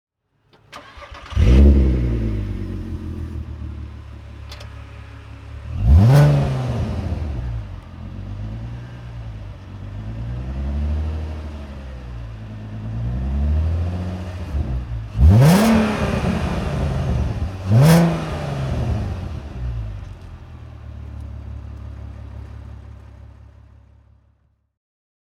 VW Golf IV R32 (2003) - Starten und Leerlauf